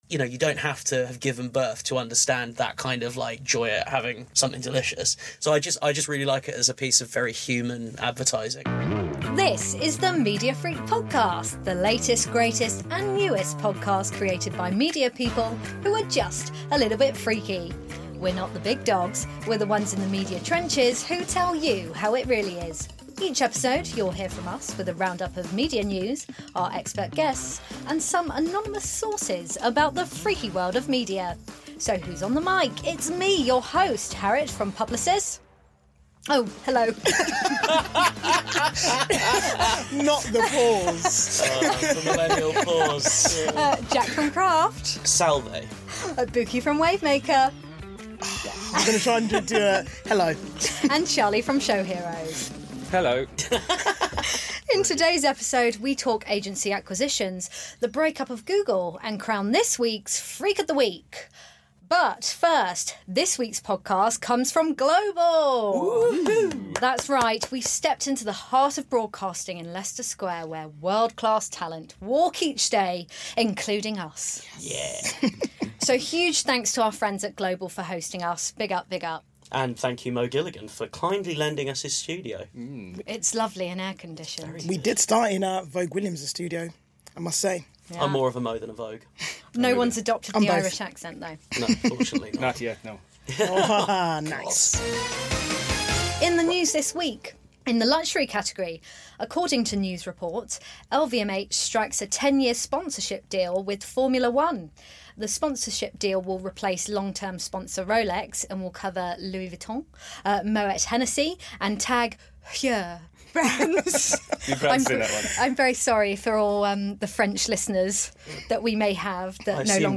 This is the marketing and advertising podcast presented by a team from across the industry.
This week we are at the incredible studios of Global in Leicester Square, home to such broadcasting titles as Capital, Smooth, LBC and Classic FM. In this episode the team are talking about the latest media news including the latest round of agency acquisitions, and the possible break up of Google.